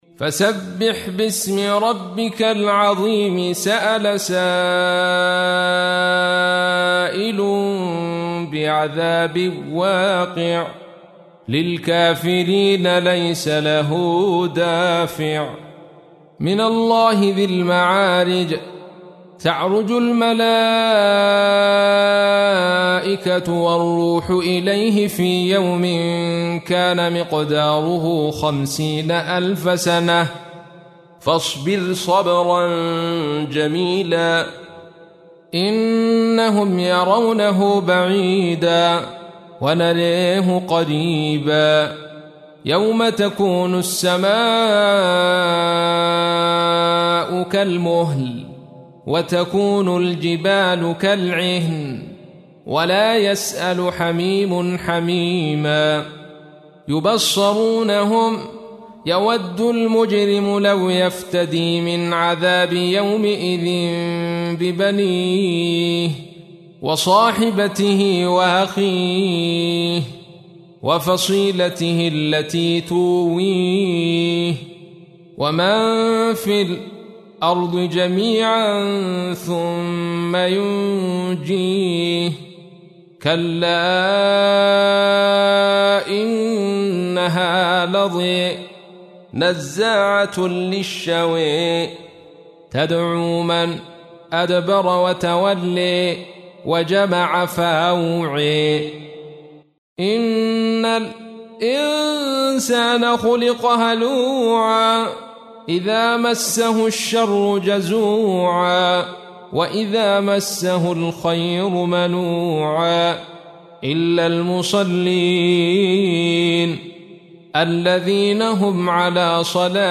تحميل : 70. سورة المعارج / القارئ عبد الرشيد صوفي / القرآن الكريم / موقع يا حسين